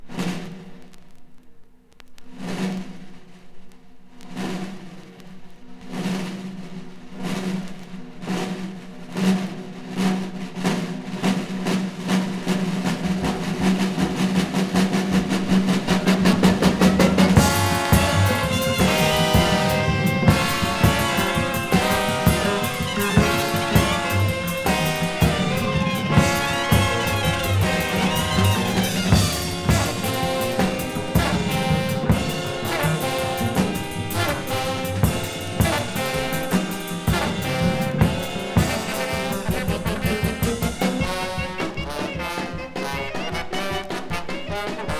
Jazz, Free Jazz　Netherlands　12inchレコード　33rpm　Stereo